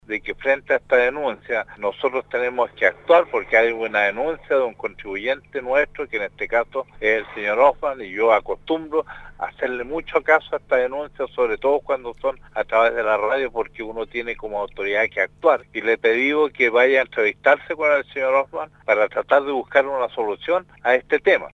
CARLOS MANSILLA, Alcalde de Puerto Octay,  señaló en diálogo con RADIO SAGO que